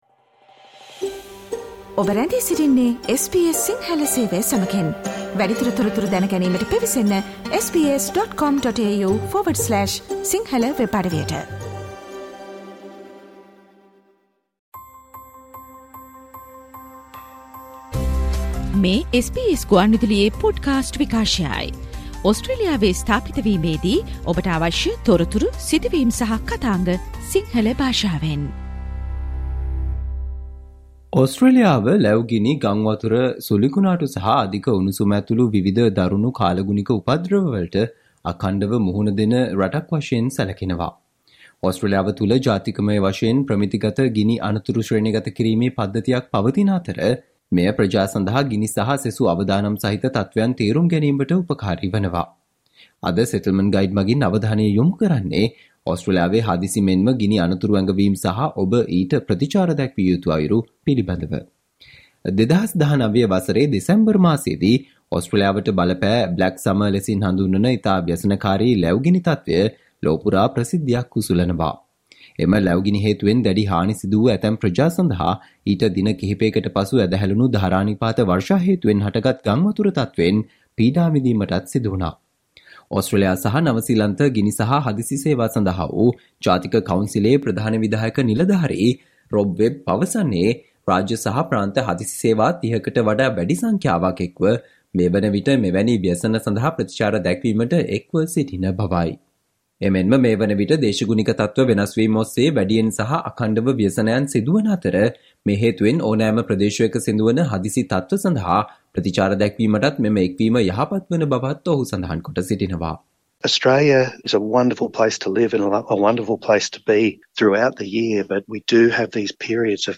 අද මෙම Settlement Guide ගුවන්විදුලි විශේෂාංගයෙන් අපි ඔබ වෙත ගෙන ආ තොරතුරු, කියවා දැන ගැනීමට හැකි වන පරිදි වෙබ් ලිපියක් ආකාරයටත් අපගේ වෙබ් අඩවියේ පලකොට තිබෙනවා.